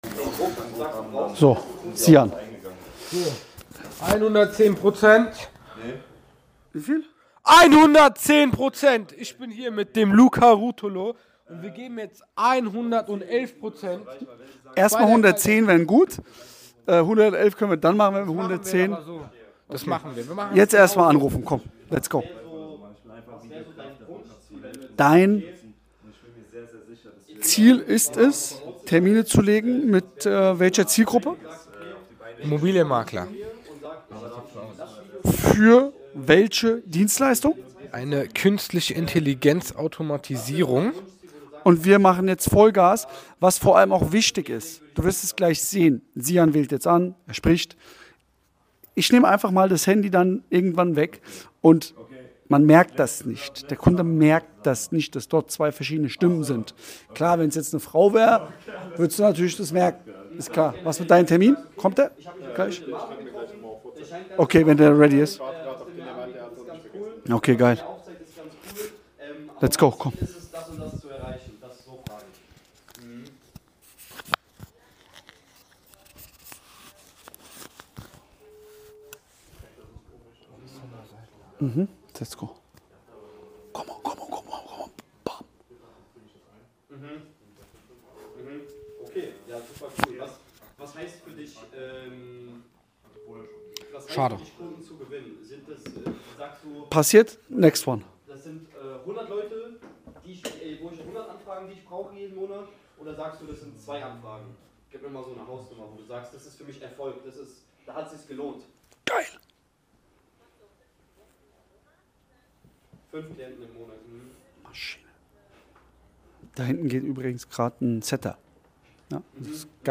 #320 Salesweek: Live Kaltakquise für Reels – Vertrieb, Marketing, Mindset und Verkauf mit DURUCAST – Lyssna här